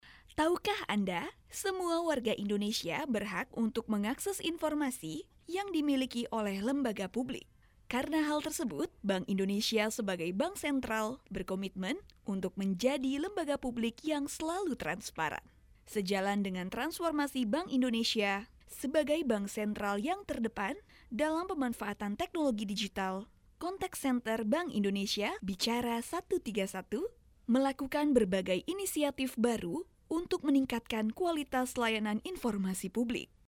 清新舒爽